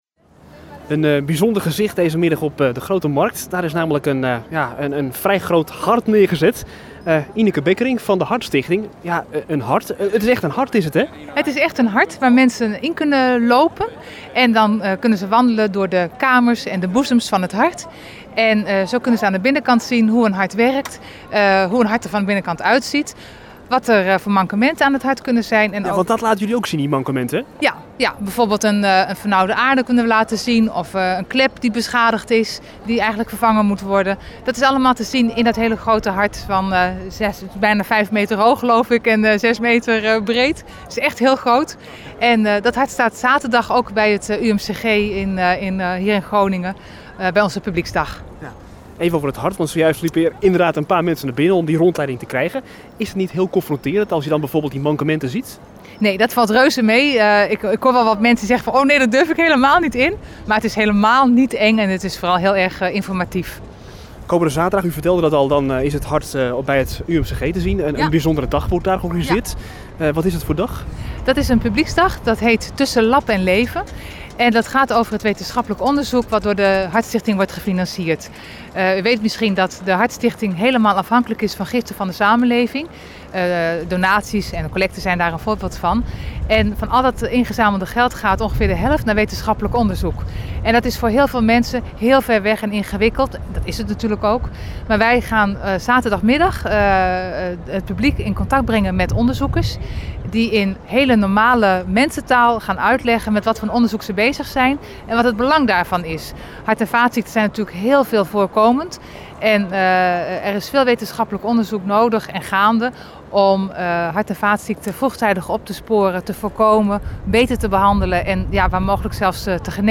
Stadjers hebben woensdag op een educatieve manier kennis kunnen maken met de werking van het hart. Op de Grote Markt was daarvoor een levensgroot hart neergezet.